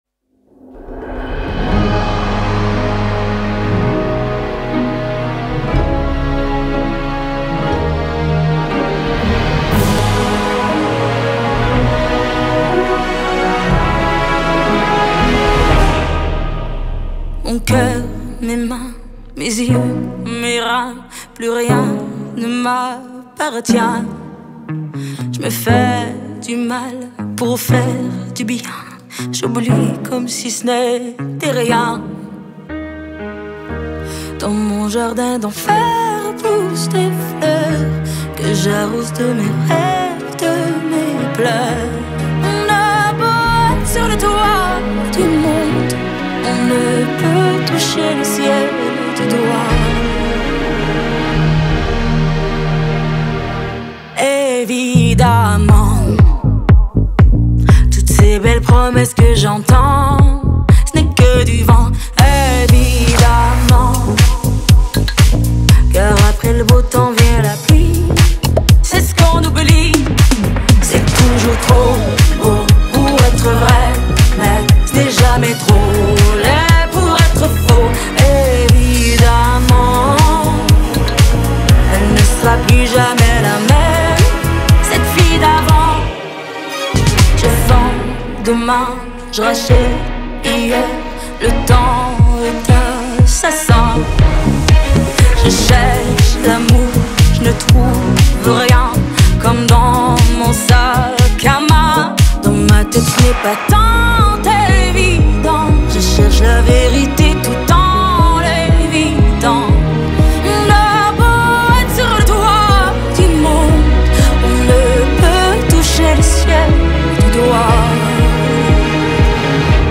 در سبک پاپ خونده شده